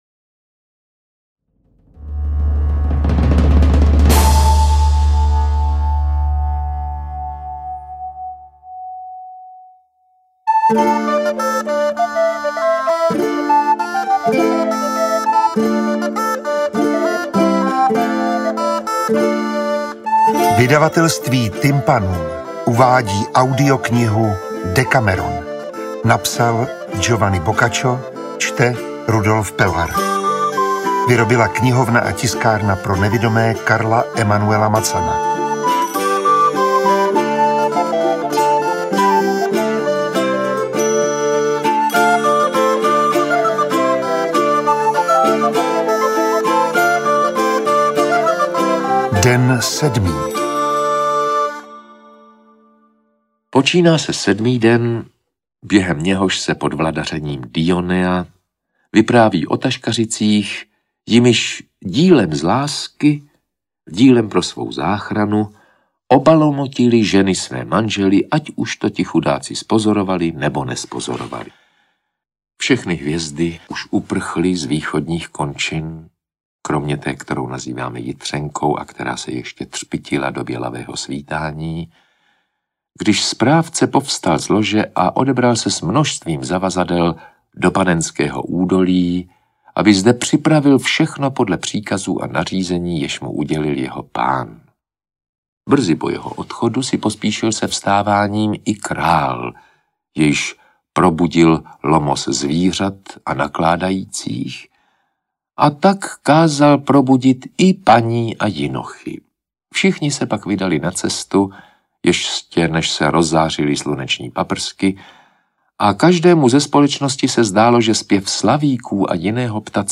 Interpret:  Rudolf Pellar
AudioKniha ke stažení, 13 x mp3, délka 2 hod. 47 min., velikost 152,0 MB, česky